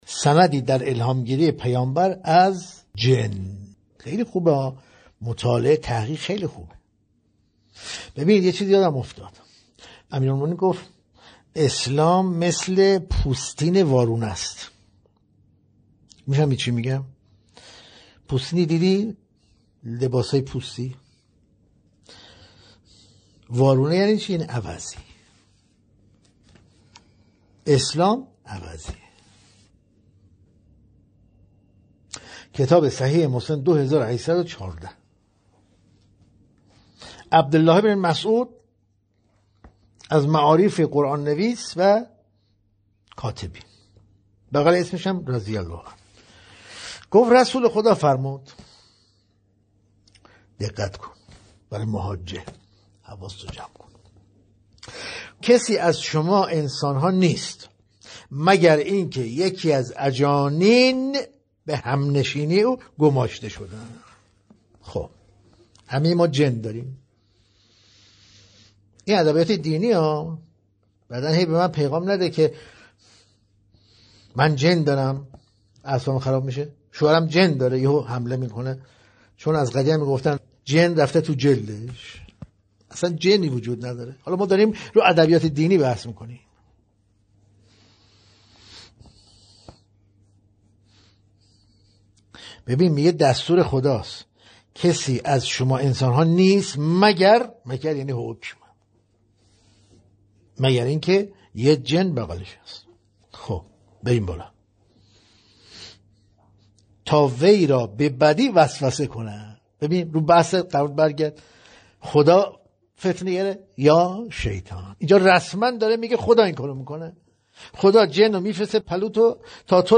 تدریس‌های روزانه